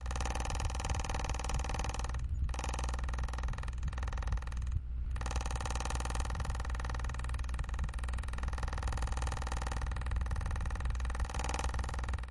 风扇 " 有东西卡在风扇里了 03
描述：有些东西被风扇困住了。
Tag: 风能 呼吸机 风扇 空气 stucked 吹制 通风口